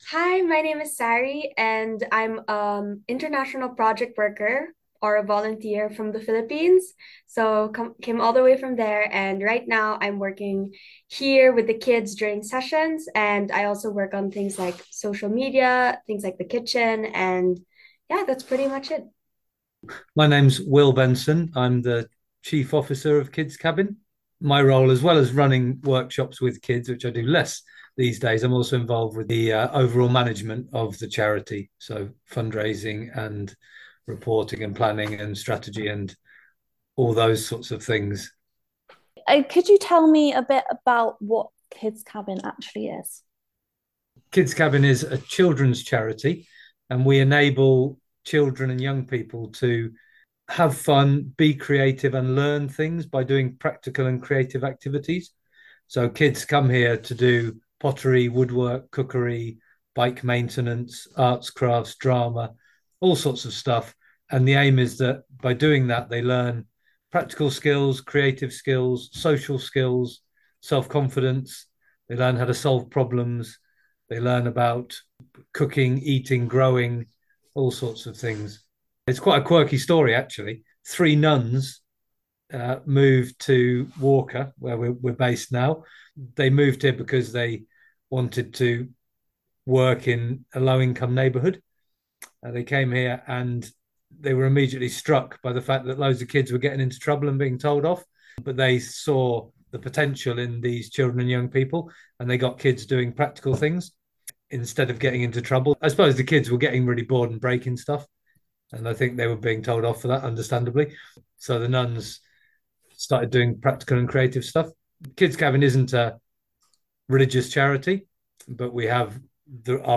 But in case you missed it, the Radio Tyneside team has been kind enough to share with us to full 22-minute interview, which you can listen to right here!